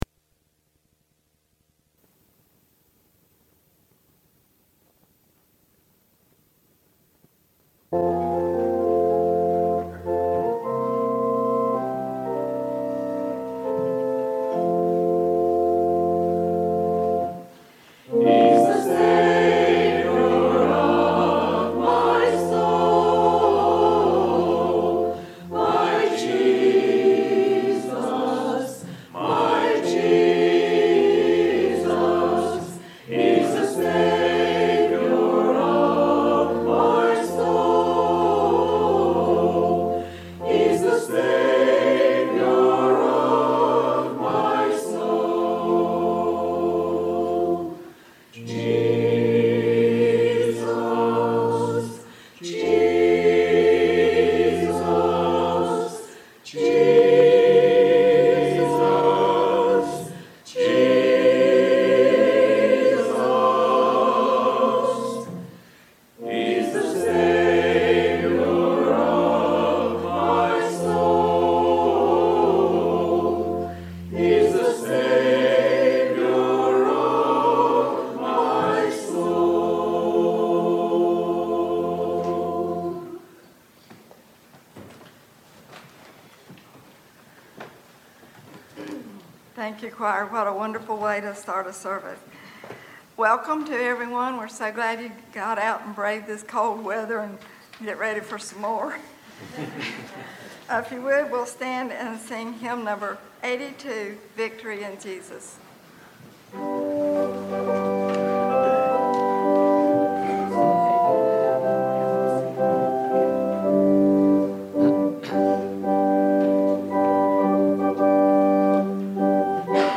There are some blank spots in the sermon due to some technical difficulties. There is also one long blank spot for about a minute.